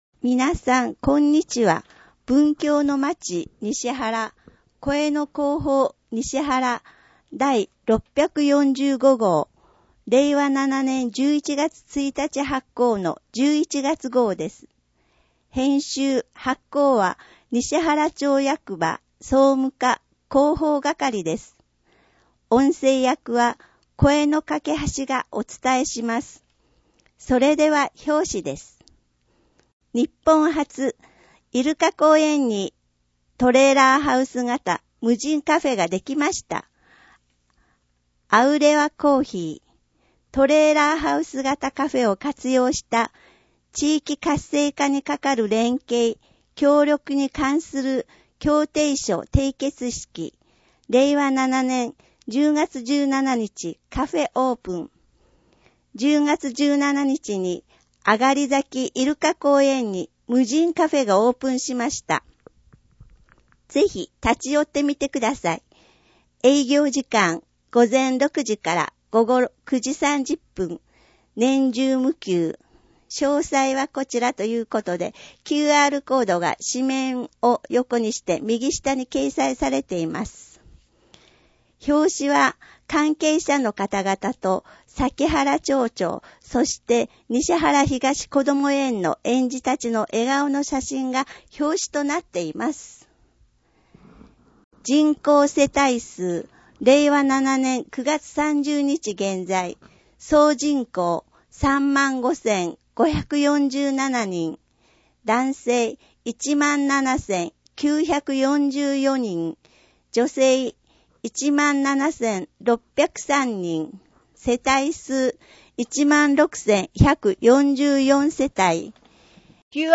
声の広報にしはらは、広報にしはらの情報を音声でお届けしています。
音訳ボランティアサークル「声の広報かけはし」が録音しています。